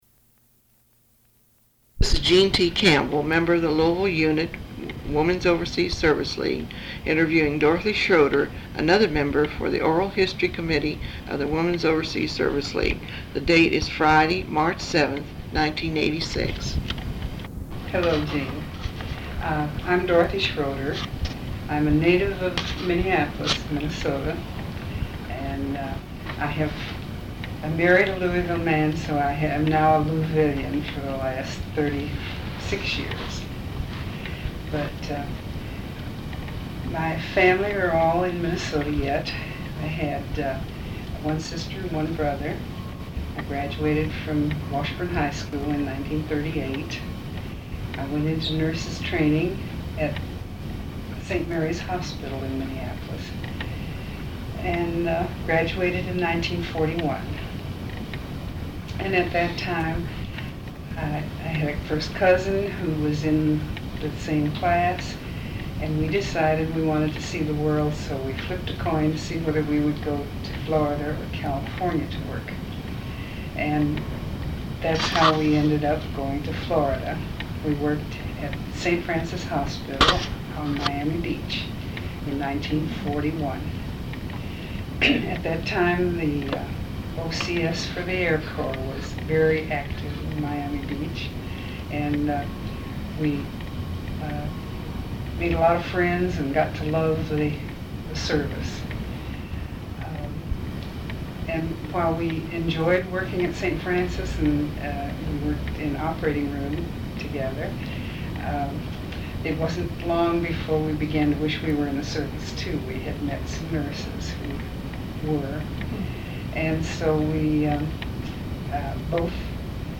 Sound recordings Interviews